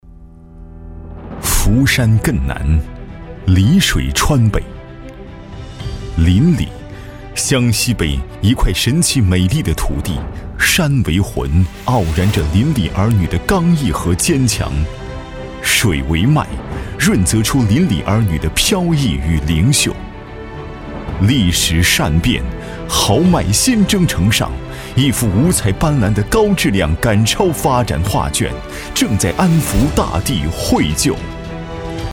76男-千亿园区产业新城-飞乐传媒官网